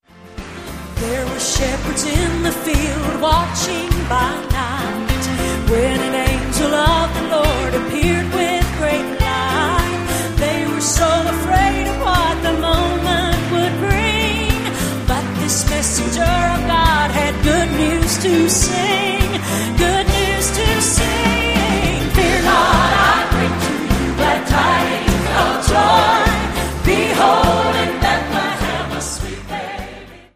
• Sachgebiet: Southern Gospel